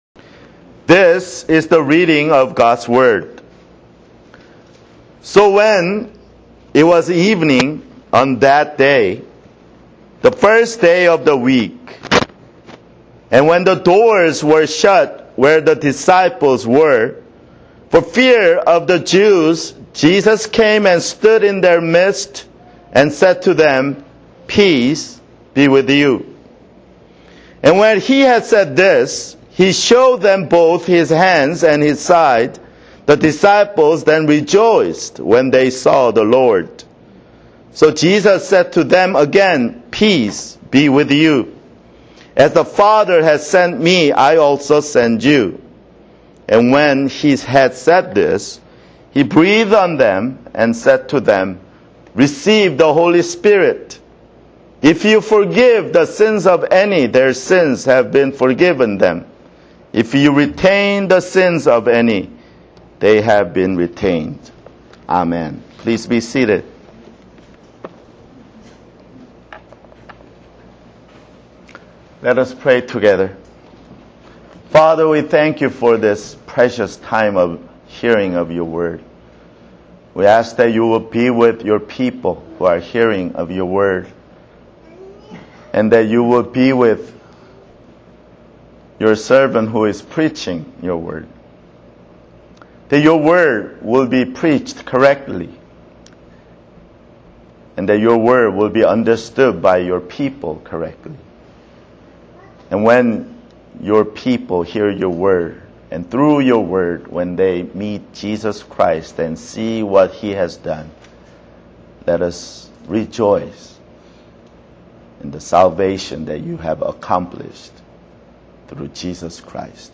[Sermon] John 20:19-23 (2)